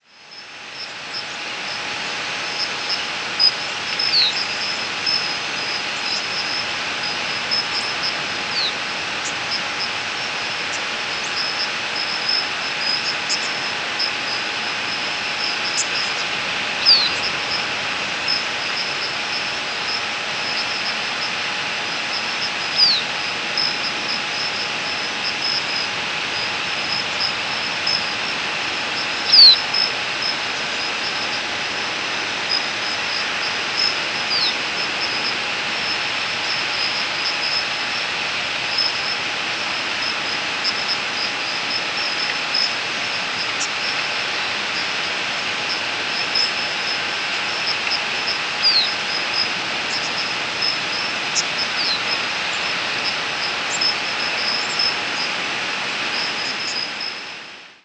Bicknell's Thrush nocturnal
Nocturnal flight call sequences:
Two presumed Bicknell's Thrushes calling in nocturnal migration amidst many warblers (mostly Common Yellowthroat) and cricket noise.